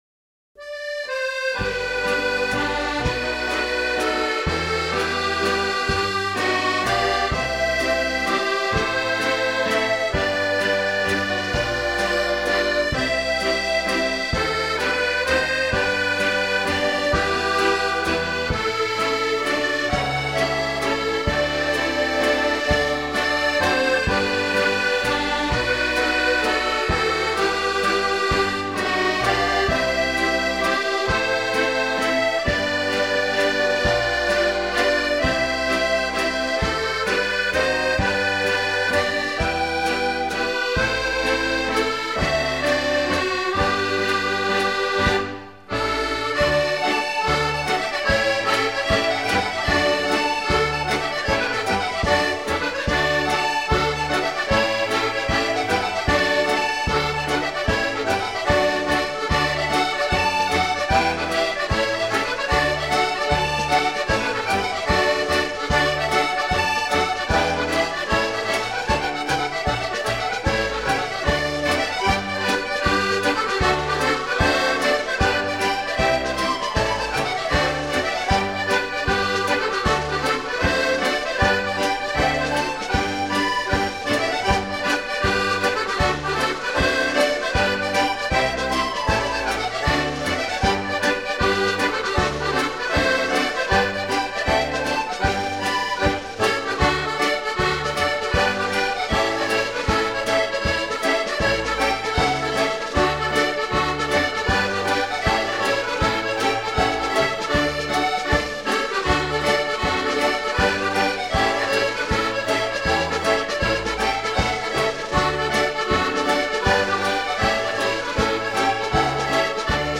Genre: Scottish.